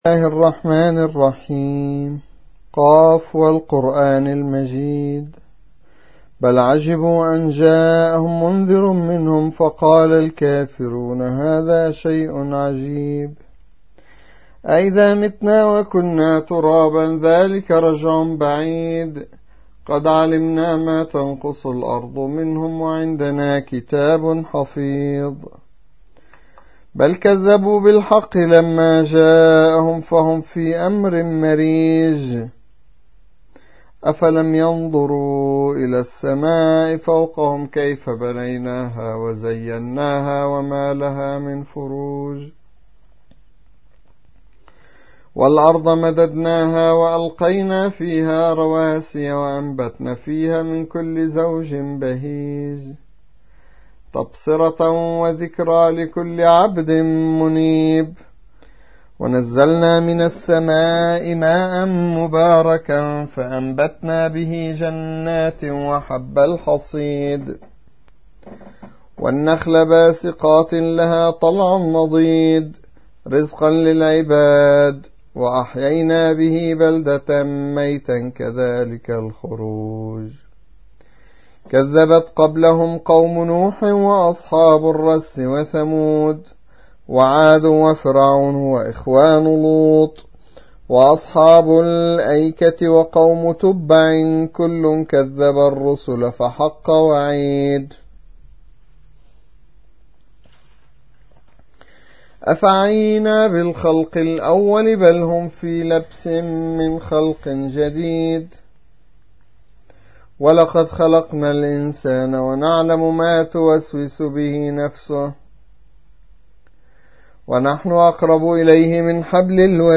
سورة ق / القارئ